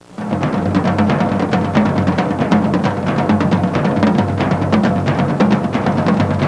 Drums Sound Effect
Download a high-quality drums sound effect.
drums.mp3